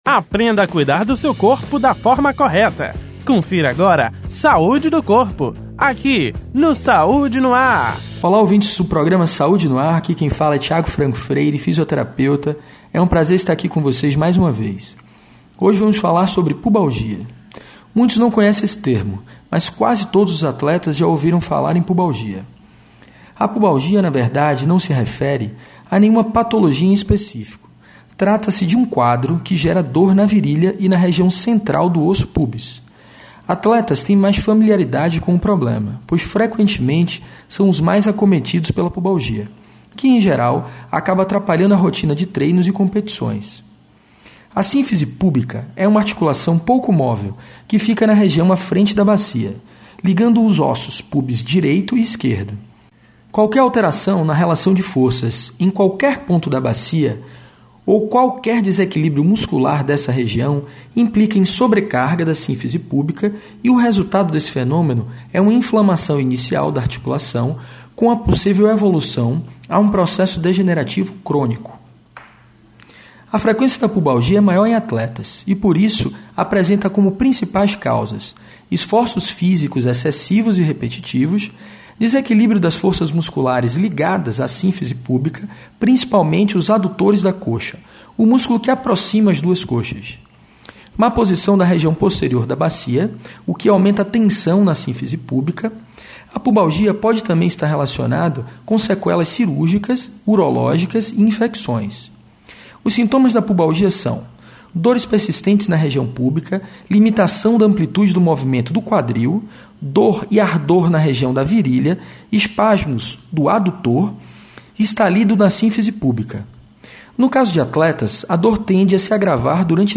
O quadro Saúde do Corpo é exibido toda segunda-feira no Programa Saúde no ar, veiculado pela Rede Excelsior de Comunicação: AM 840, FM 106.01, Recôncavo AM 1460 e Rádio Saúde no ar / Web.